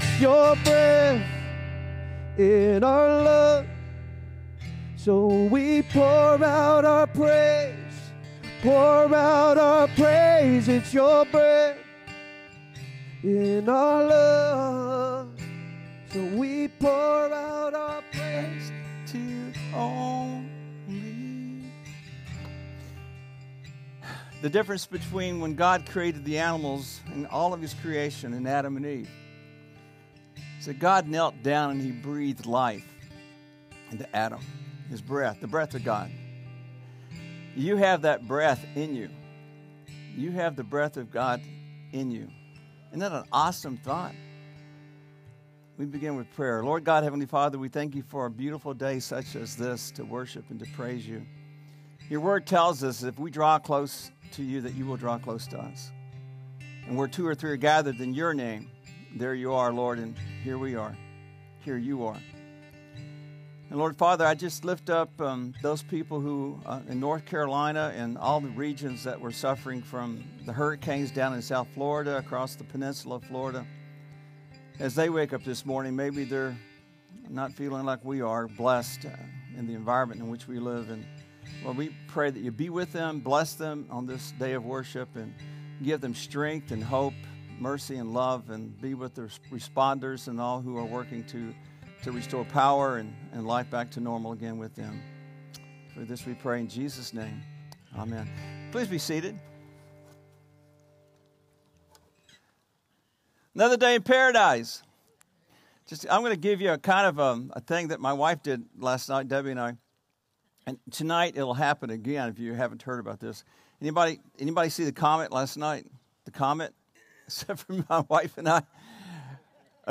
SERMON DESCRPTION There are an estimated 4,200 different religions in the world; Christianity being one of them.